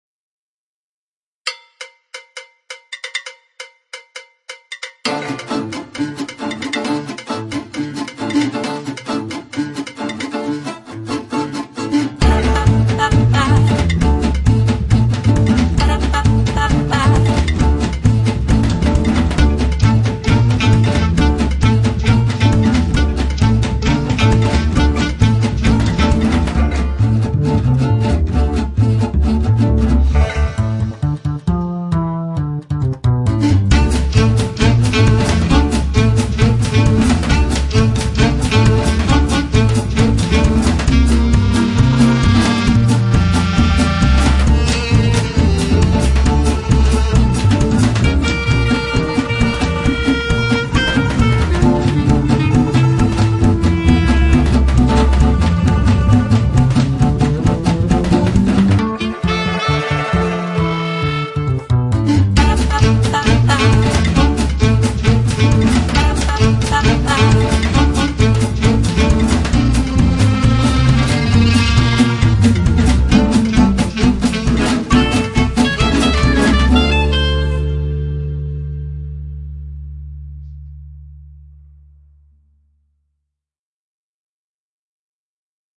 Instrumental with NO COMPOSER mentioned!!!!